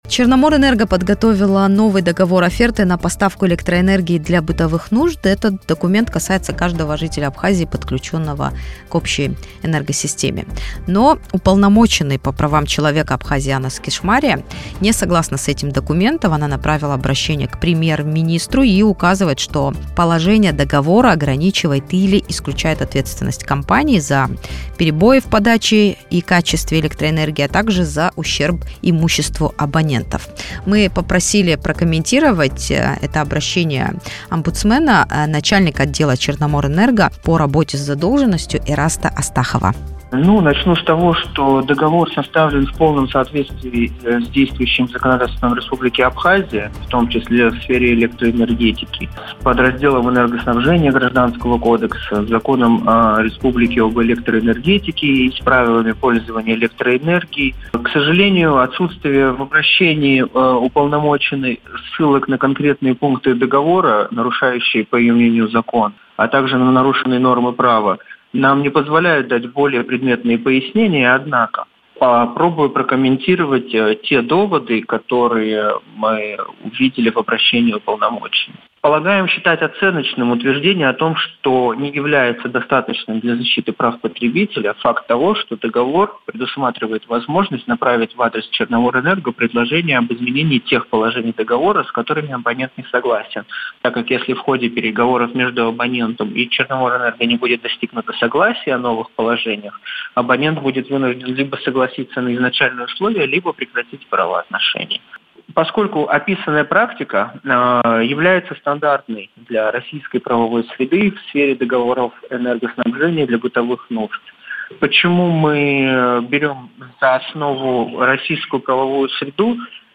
Договор оферты на поставку электроэнергии: комментарий юриста "Черноморэнерго"